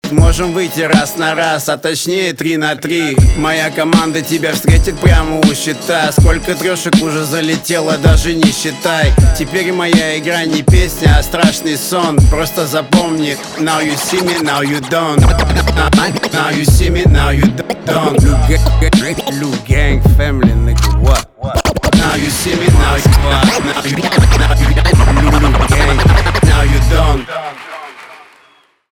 русский рэп
битовые , басы , пацанские , качающие